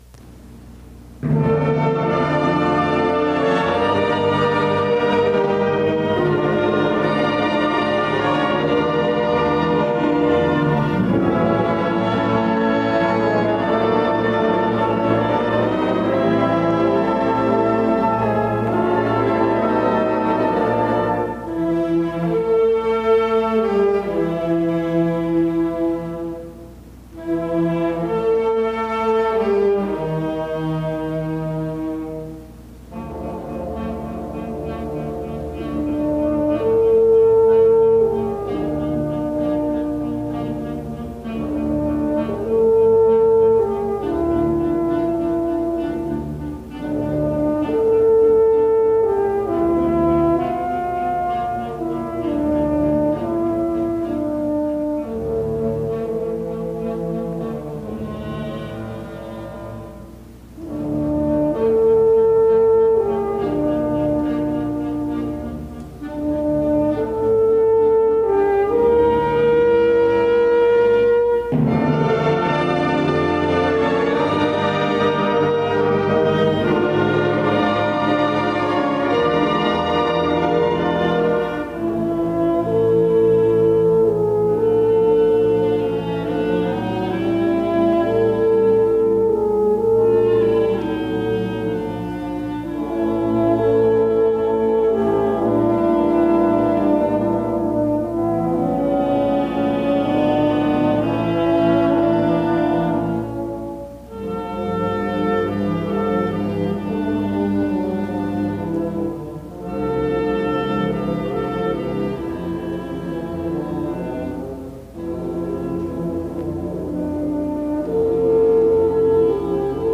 I was honored to play a solo in a concert one of my last years at Wesleyan. The recording is not great (mostly because of my old tape deck).